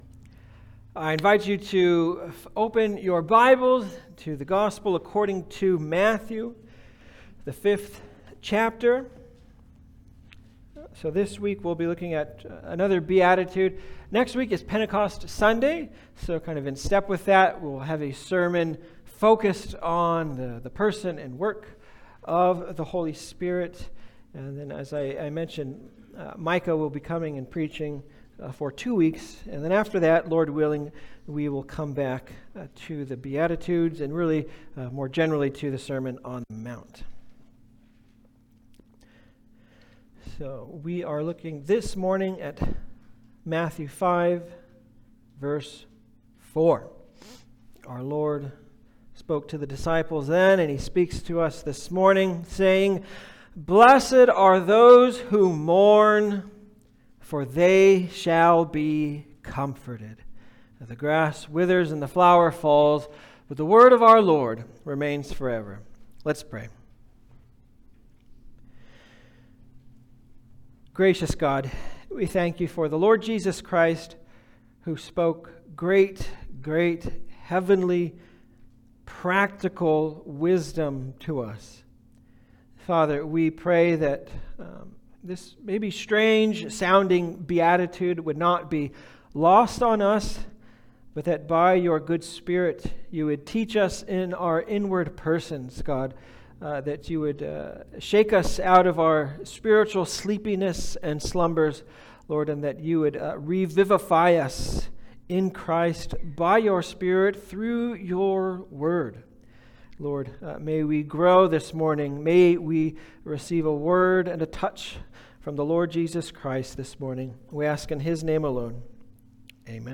Passage: Matthew 5:4 Service Type: Sunday Service